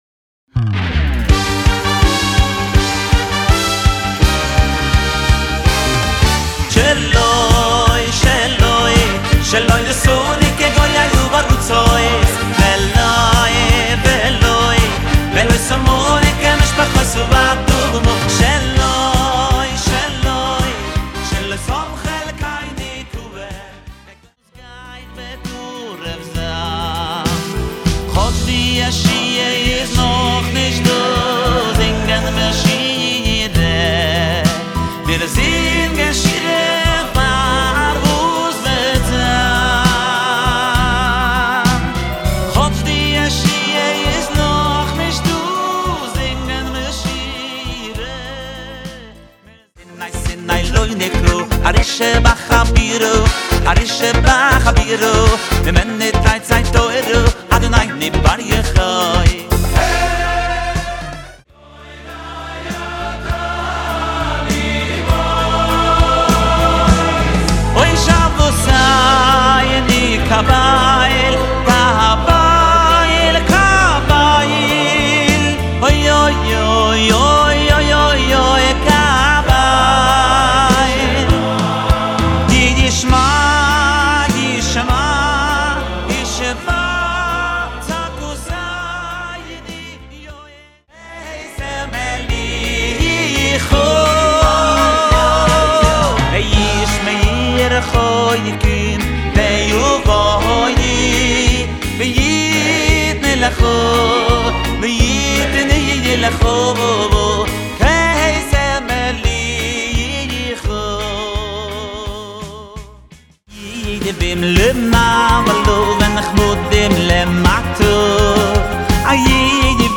ומקהלת הילדים